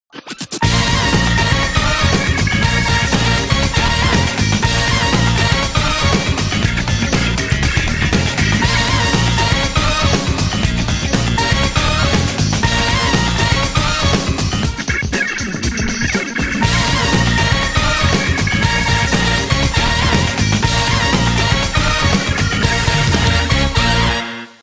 • Качество: 320, Stereo
громкие
веселые
без слов
инструментальные